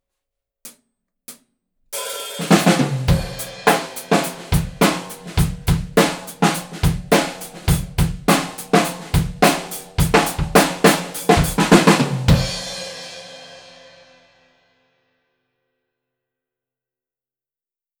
ドラムセットは、Canopusyaiba kit
すべて、EQはしていません。
①アンビエンス　約２m
まず、２ｍぐらい離したところにマイキングしてみました。
部屋鳴り感が出ていますね！